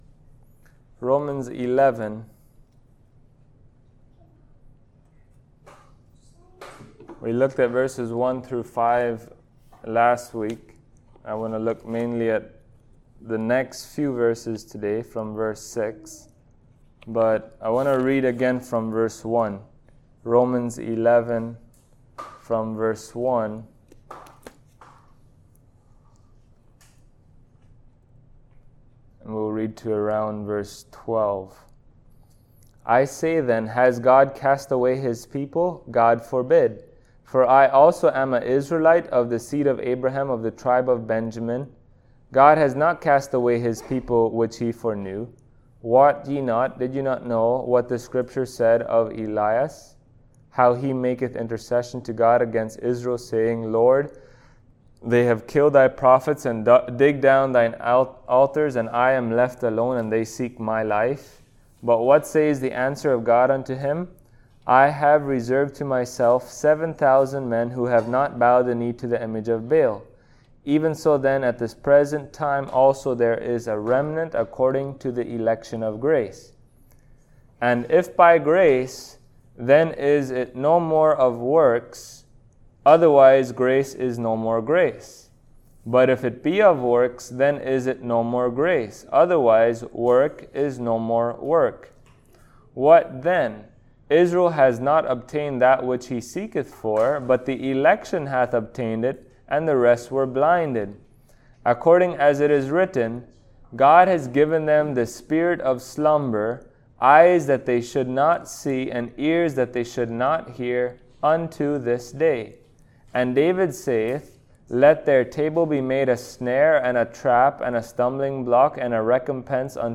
Service Type: Sunday Morning Topics: Election , Grace , Salvation , Works « Salvation